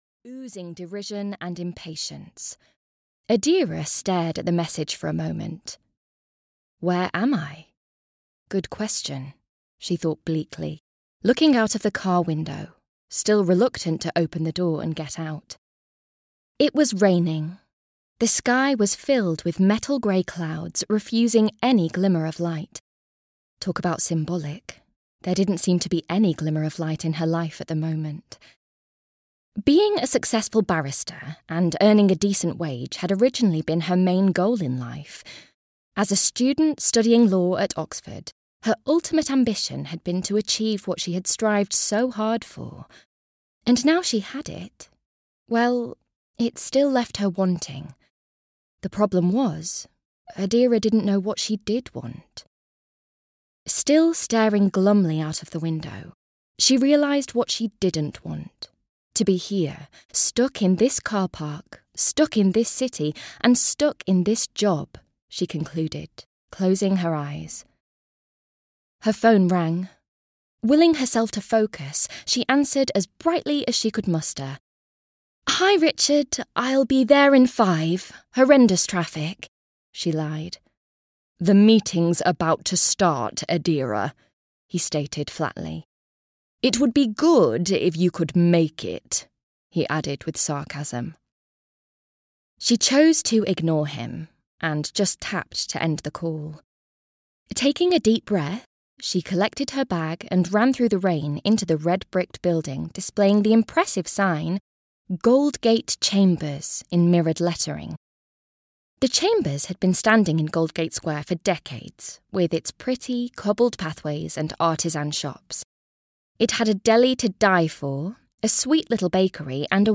Escape to Lilacwell (EN) audiokniha
Ukázka z knihy